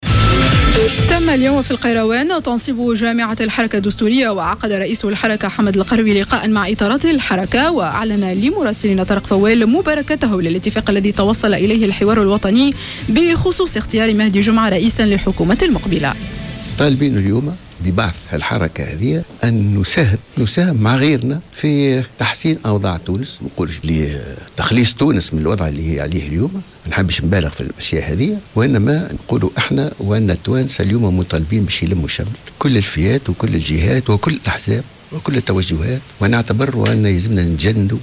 en marge d'une réunion tenue à Kairouan à l'issu de l'investiture du mouvement destourien, du choix de Mehdi Jomaa pour le poste de premier ministre.